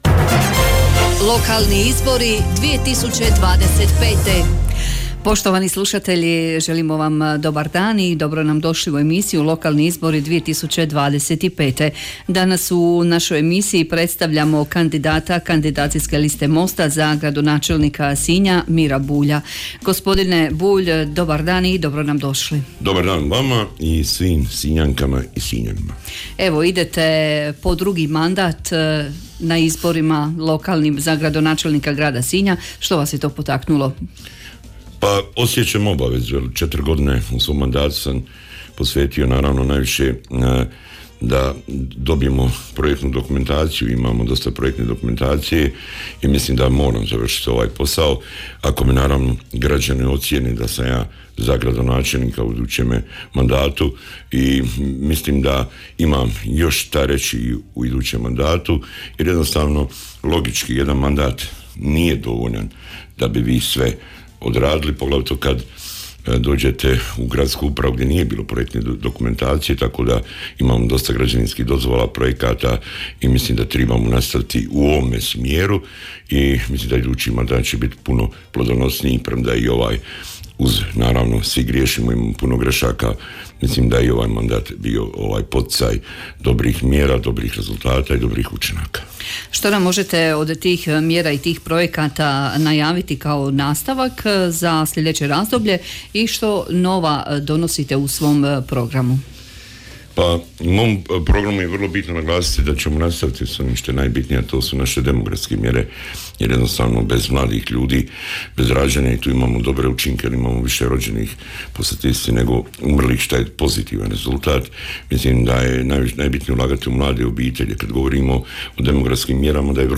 Sve kandidacijske liste i svi kandidati za načelnike odnosno gradonačelnike tijekom službene izborne kampanje imaju pravo na besplatnu emisiju u trajanju do 10 minuta u studiju Hit radija.
Danas je svoj izborni program predstavio Miro Bulj, kandidat Mosta za gradonačelnika Grada Sinja.